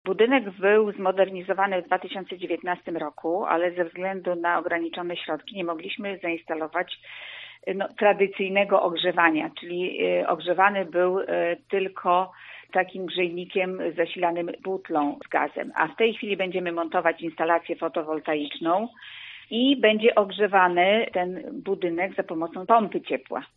O potrzebie wsparcia finansowego mówi zastępca Wójta gminy Długosiodło, Ewa Karczewska: